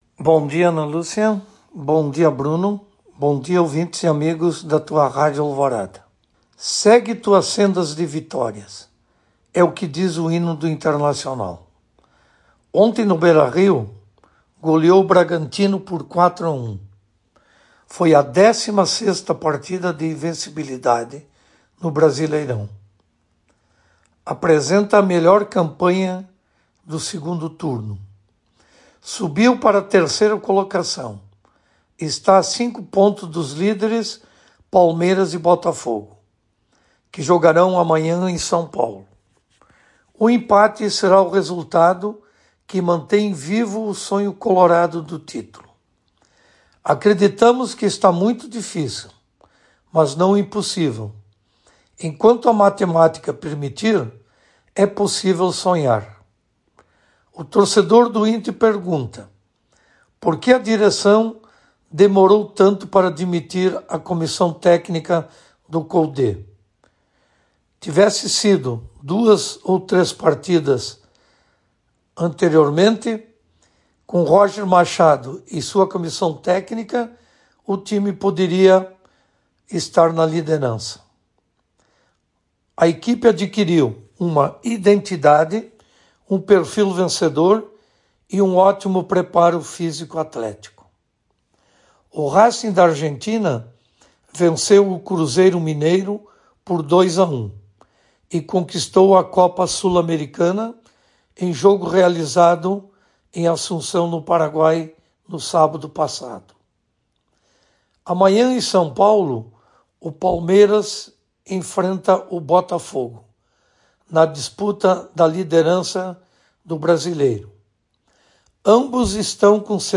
Comentário esportivo